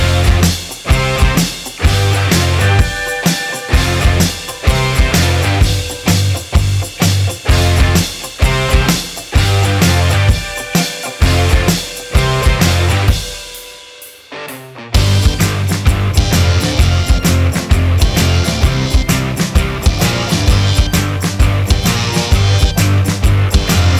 Minus Main Guitar Indie / Alternative 3:45 Buy £1.50